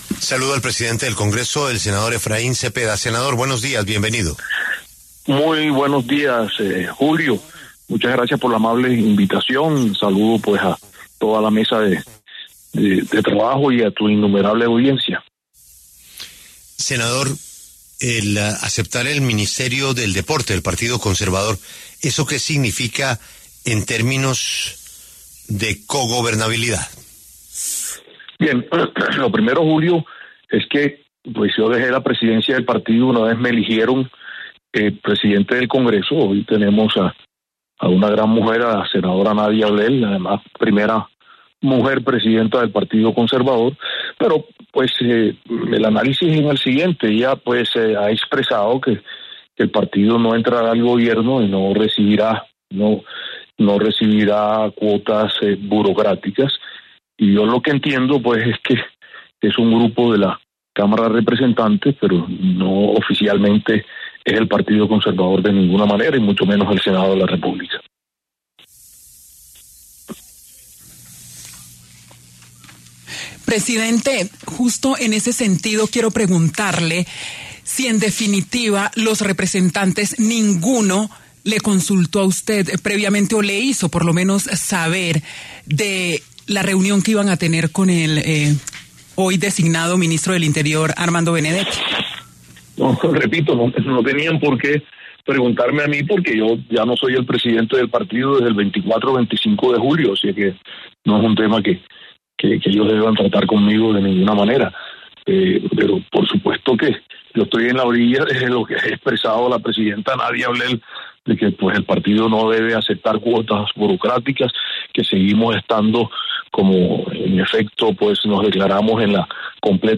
El presidente del Congreso de la República, Efraín Cepeda, habló en La W sobre las cuotas políticas del Partido Conservador en el Gobierno del presidente Gustavo Petro y la llegada de Armando Benedetti al gabinete.